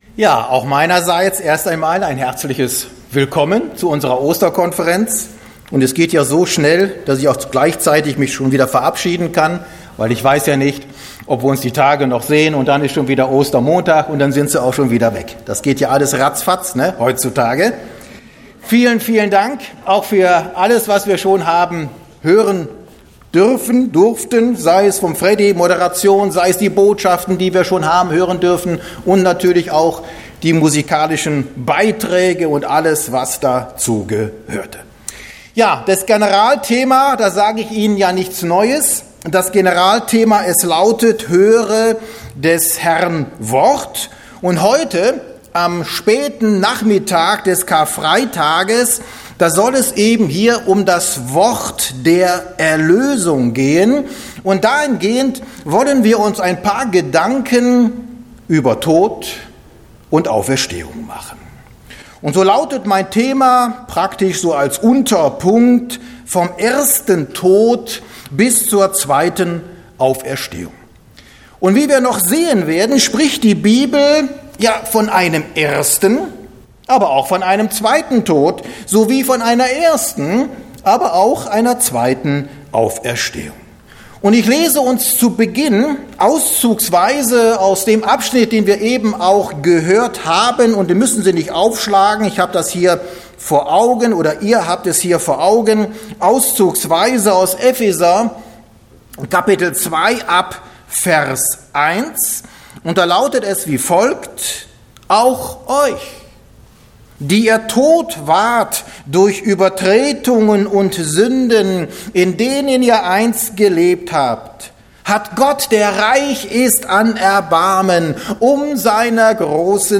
Botschaft